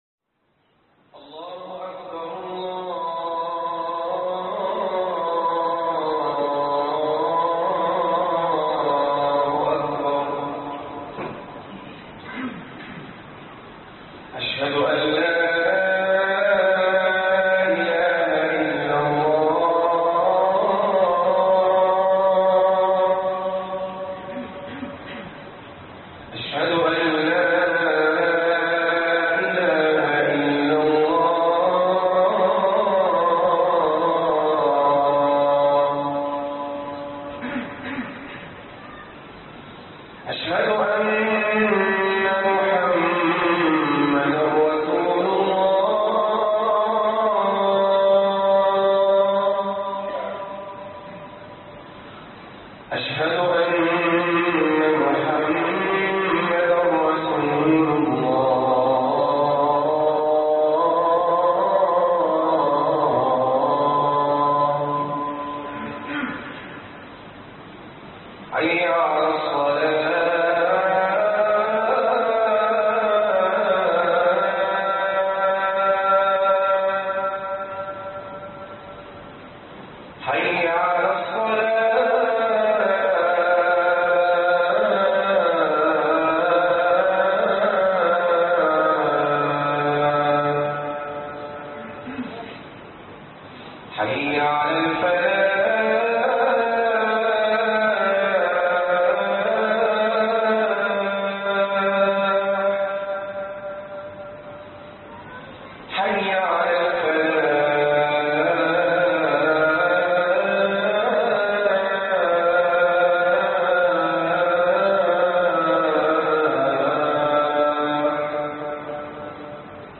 النظر فى الغربال (خطب الجمعة